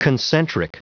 Prononciation du mot concentric en anglais (fichier audio)
Prononciation du mot : concentric